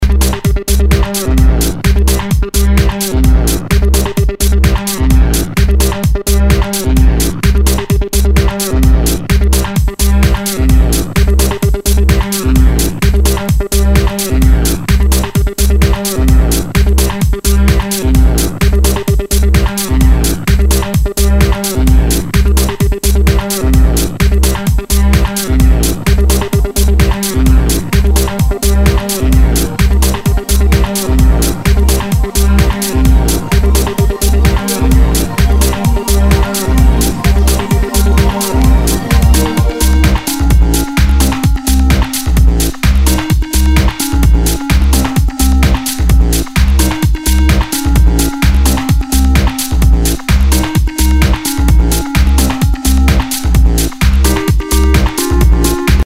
HOUSE/TECHNO/ELECTRO
ナイス！テック・ハウス！